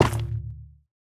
Minecraft Version Minecraft Version 1.21.5 Latest Release | Latest Snapshot 1.21.5 / assets / minecraft / sounds / block / shroomlight / break5.ogg Compare With Compare With Latest Release | Latest Snapshot
break5.ogg